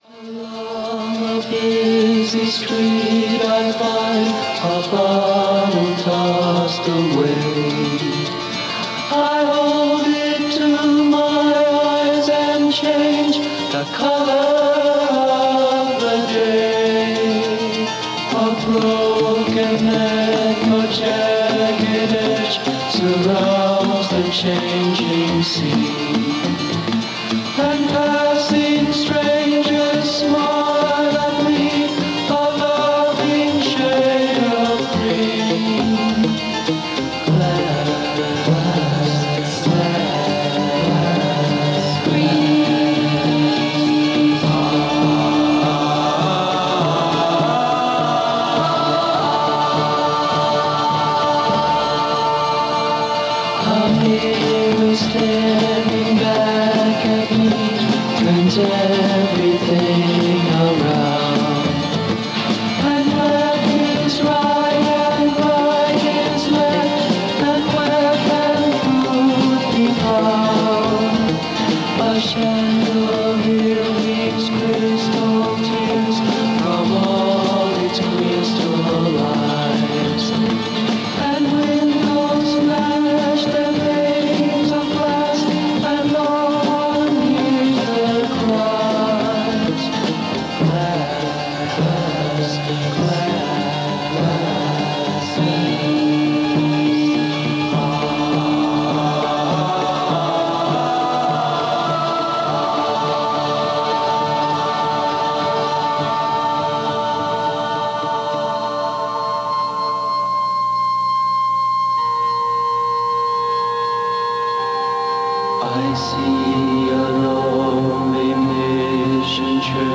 Past Daily Pop Chronicles.